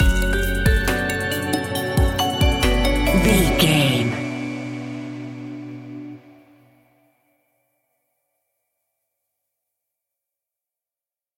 Aeolian/Minor
groovy
dreamy
peaceful
smooth
drum machine
synthesiser
synth leads
synth bass